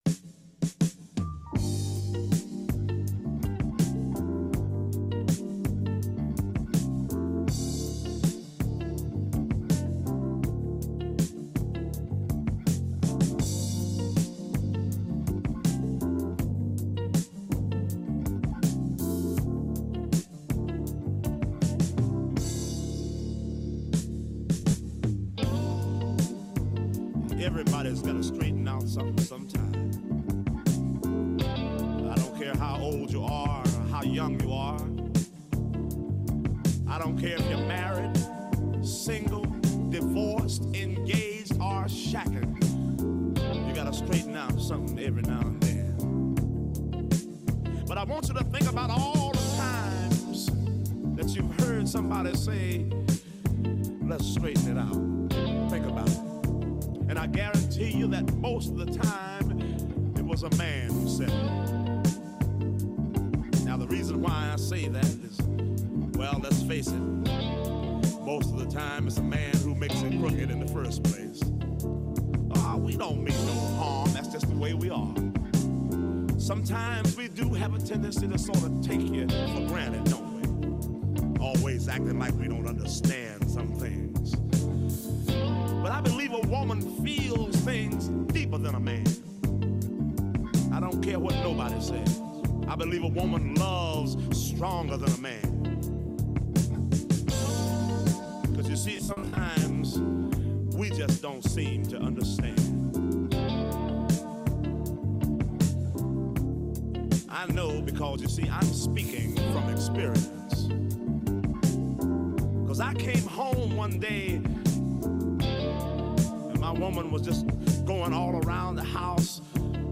Ο 9,58fm στο 64ο Διεθνές Φεστιβάλ Κινηματογράφου Θεσσαλονίκης
υποδέχονται καθημερινά στο στούντιο του 9,58fm, στην Αποθήκη Γ , στο Λιμάνι της Θεσσαλονίκης , Έλληνες και ξένους σκηνοθέτες, παραγωγούς και συντελεστές ταινιών.